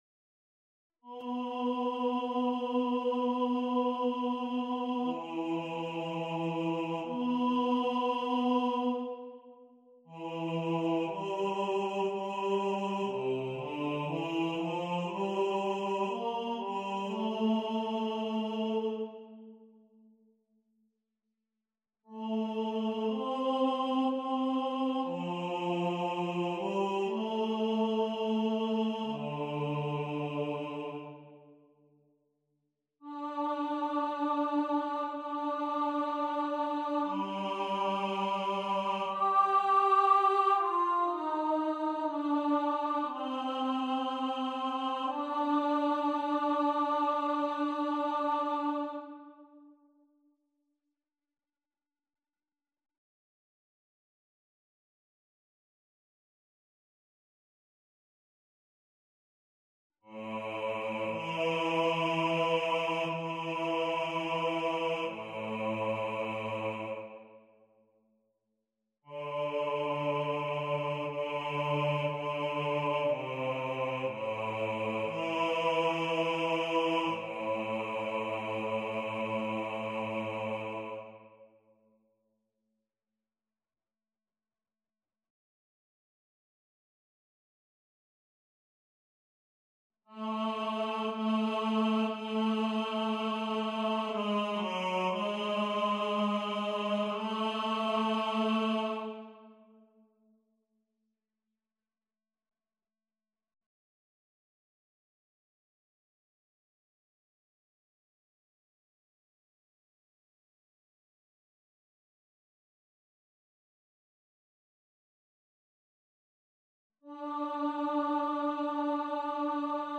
enkele melodieën uit wereldlijke weken van Josquin des Prez (overgenomen uit de uitgave v.d. Ver. v. Ned. Muziekgeschiedenis, vijfde aflevering, bundel II)